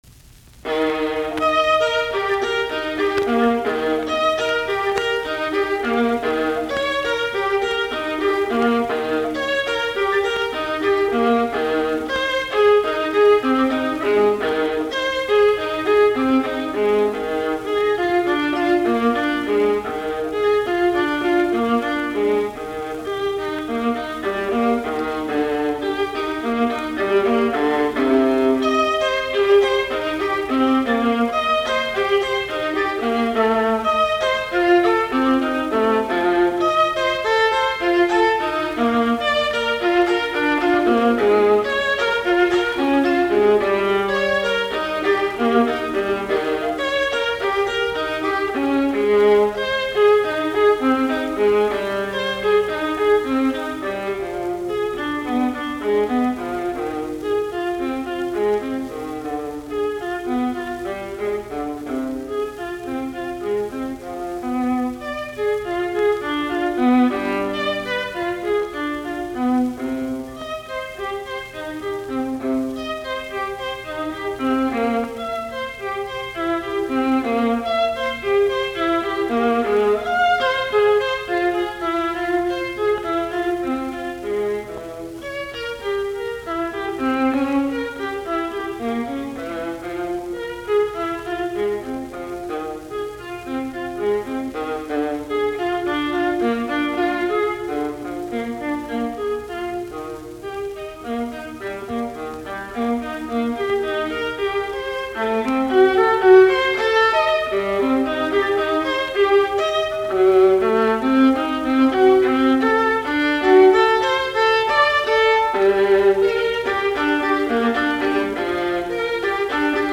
alttoviulu